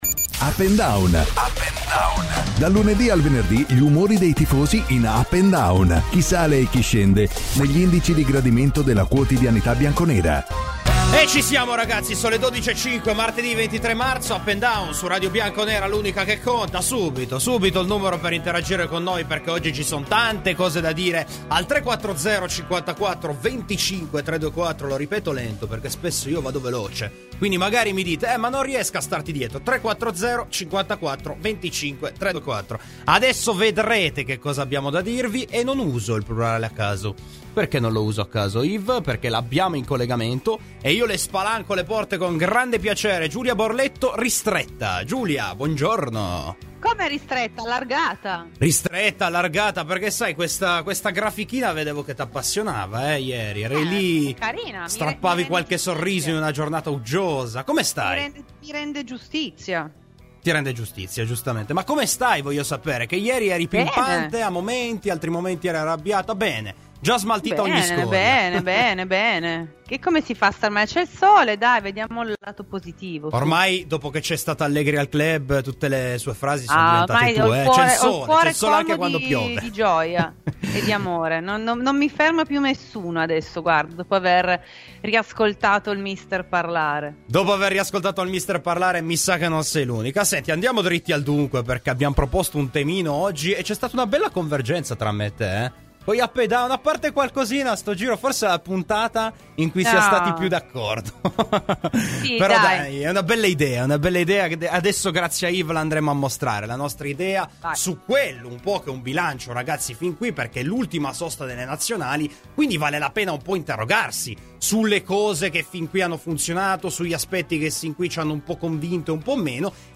Ai microfoni di Radio Bianconera, nel corso di 'Up&Down', è intervenuto l'ex portiere Dino Zoff: "Pirlo?